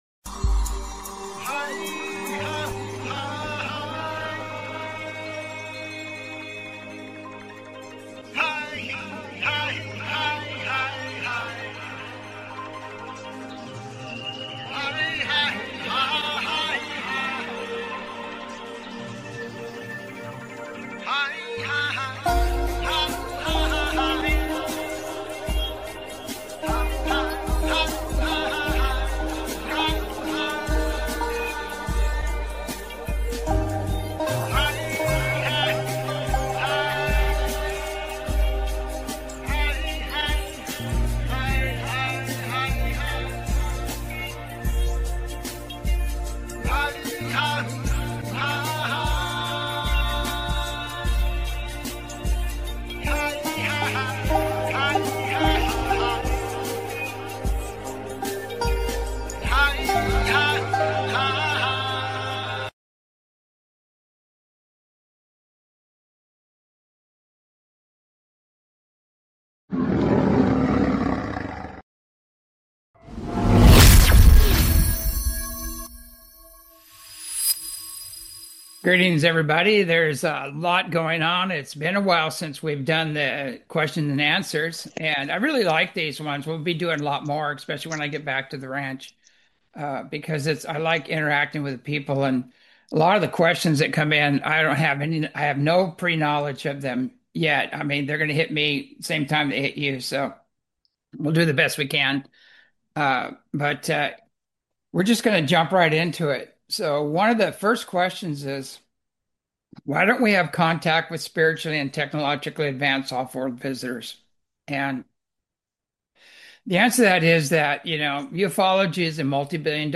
Talk Show Episode, Audio Podcast, As You Wish Talk Radio and Navigating the Controlled Narrative, Universal Law, and the Cygnus Activation on , show guests , about Crisis of Integrity,The Controlled Narrative,universal law,Cygnus Activation,Spiritual Integrity,Modern Ufology,Cosmic Shifts,Cygnus Signal,Institutional Control,Religion, categorized as Earth & Space,Entertainment,Paranormal,UFOs,Physics & Metaphysics,Politics & Government,Society and Culture,Spiritual,Theory & Conspiracy